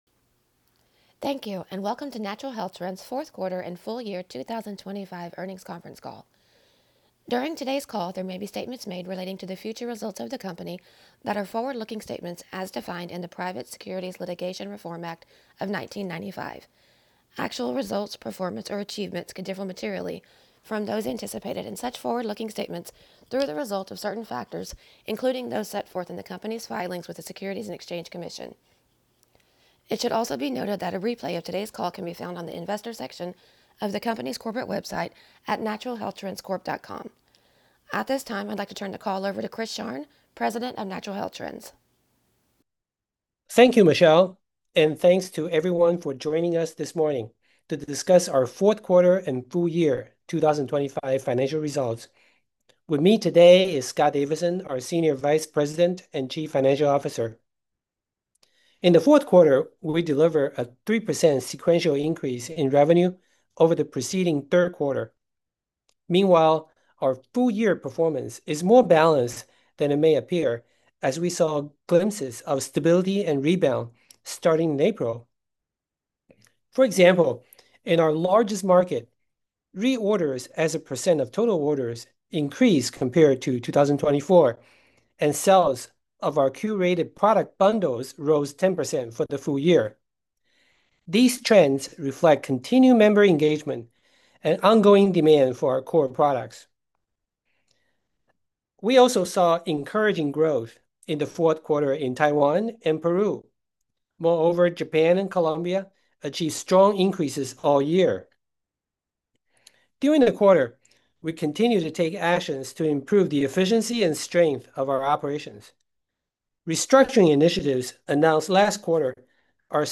NHT_Q4_2025_EARNINGS_CALL.mp3